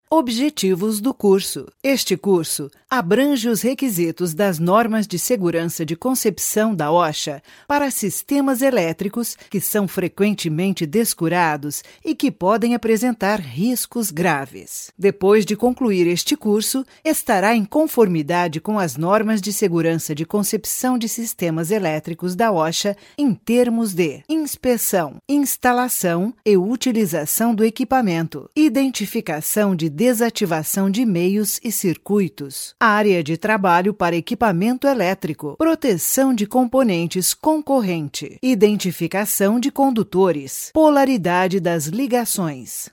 PT BR ST EL 01 eLearning/Training Female Portuguese(Brazilian)